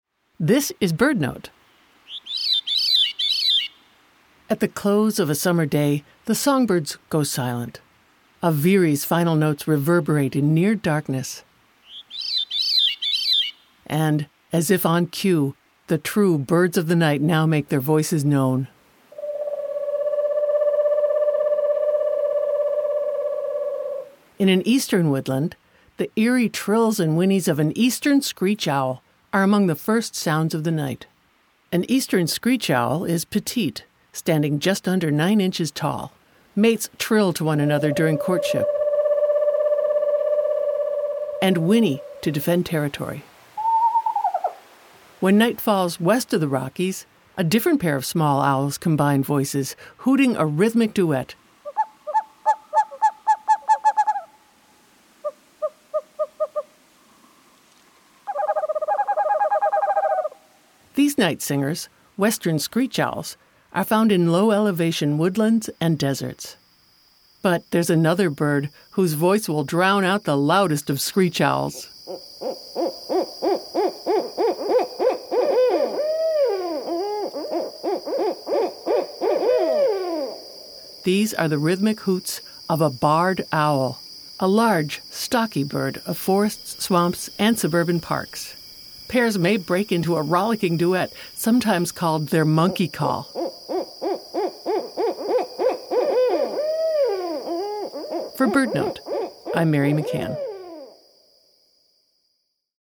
At the close of a summer day, the songbirds go silent.
In an Eastern woodland, the eerie trills and whinnies of an Eastern Screech-Owl are among the first sounds of the night. Meanwhile, as night falls west of the Rockies, a Western Screech-Owl calls out.
Pairs may break into a rollicking duet, sometimes called their “monkey call.”